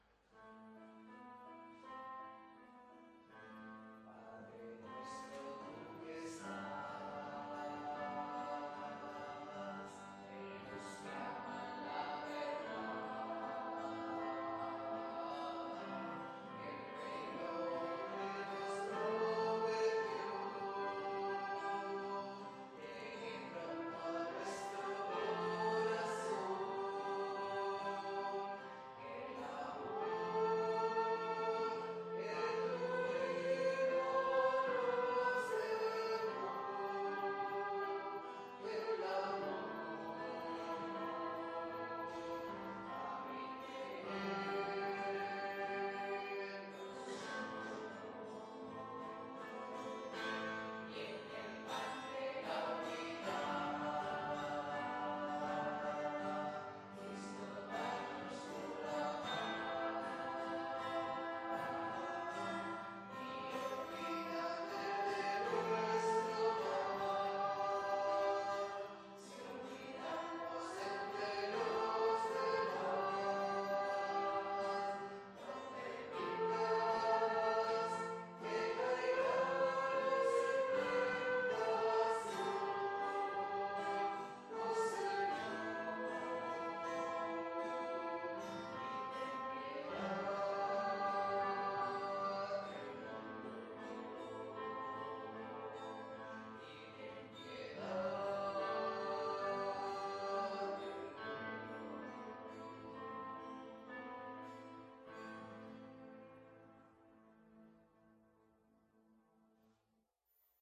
Parròquia de Maria Auxiliadora - Diumenge 30 de setembre de 2018
Vàrem cantar...